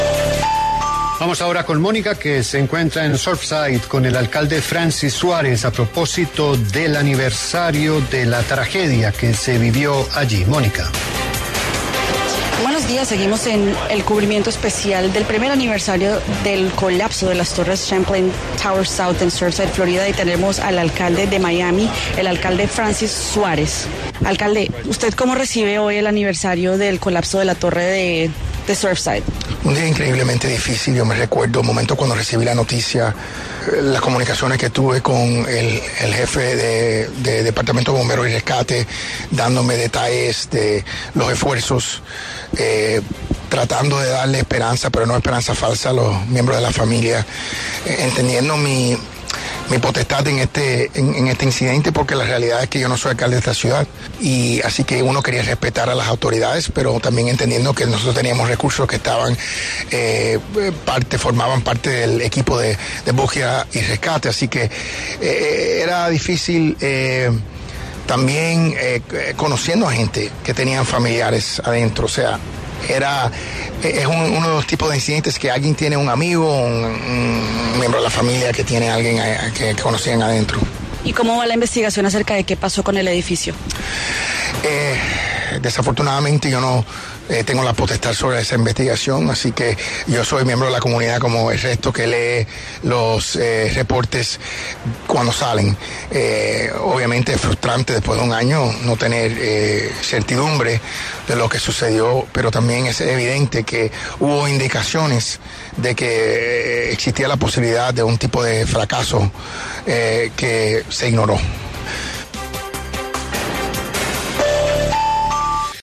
Francis Suárez, alcalde de Miami, pasó por los micrófonos de W Radio para hablar sobre el aniversario de la tragedia del colapso del edificio Surfside en el que fallecieron 98 personas.